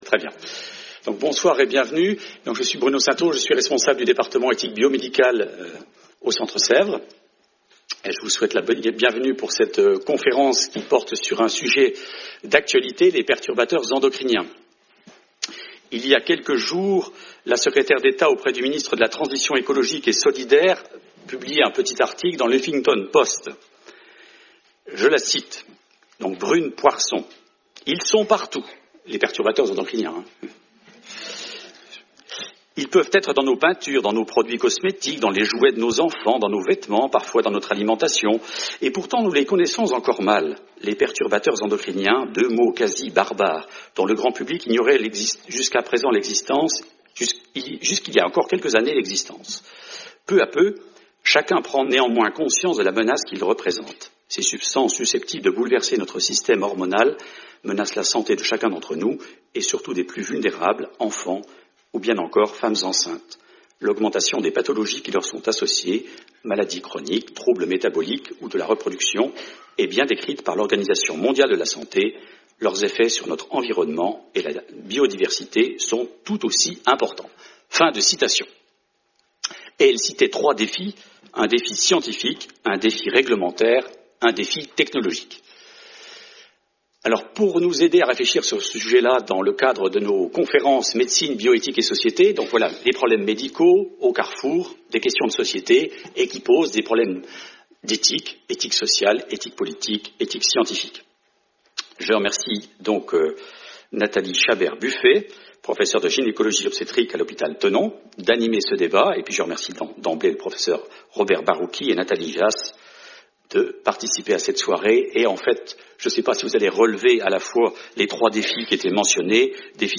Débats